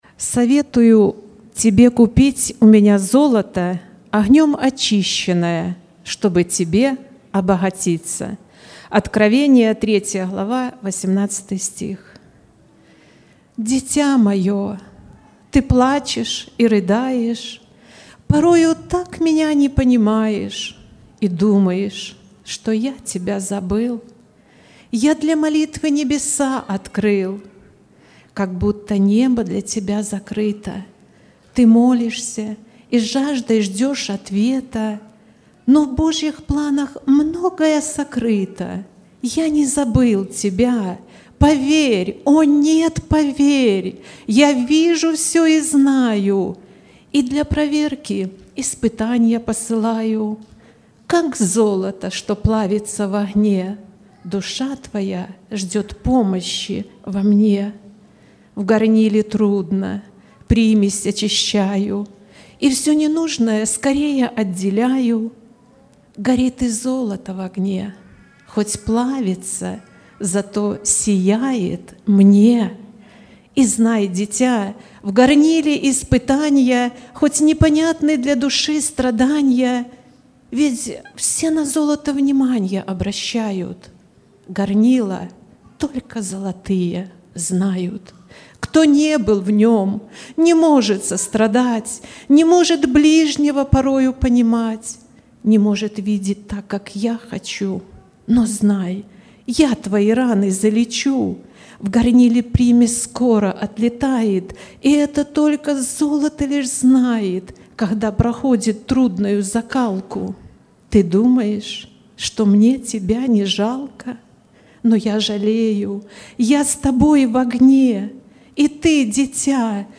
05-07-23 Воскресение — Церковь «Путь ко Спасению»
04+Стих+-+Дитя+Мое!+Ты+плачешь+и+рыдаешь.mp3